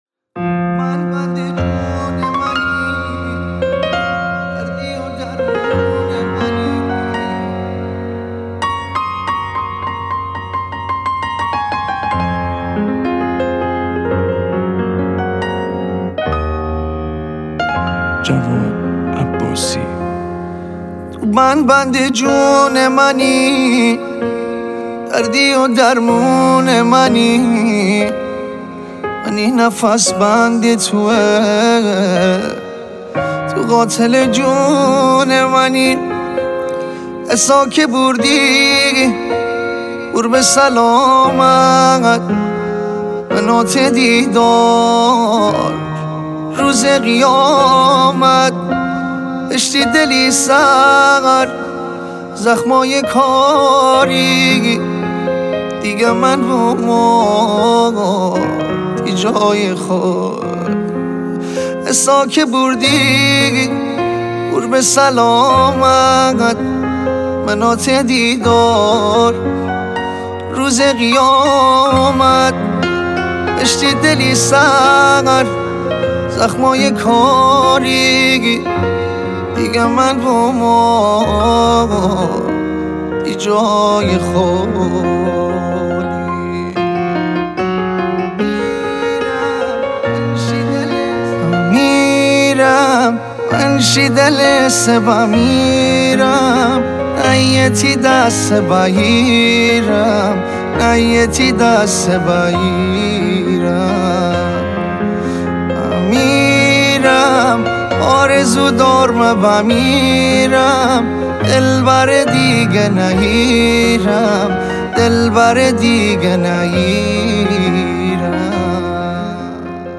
غمگین
آهنگی در سبک آهنگ های غمگین مازندرانی